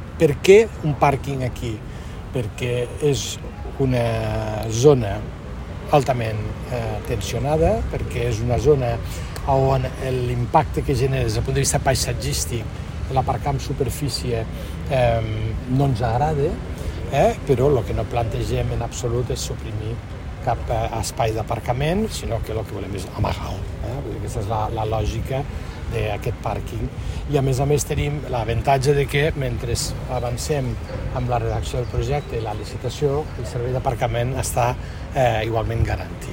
Talls de veu